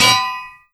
minecraft_anvil.wav